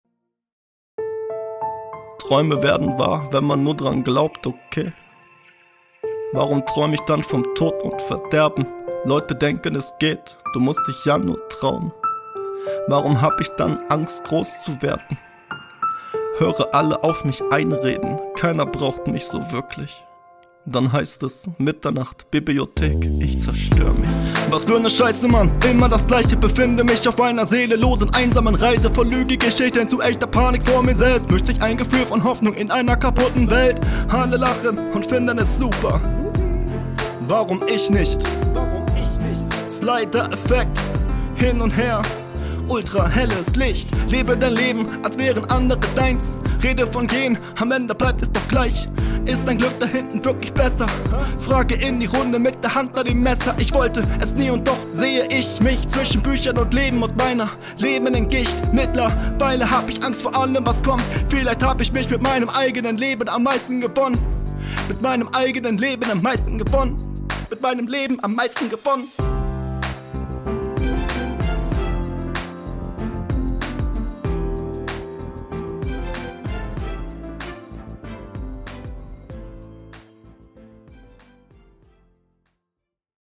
Der Song zum Podcast!
musikalische Untermalung des Podcasts.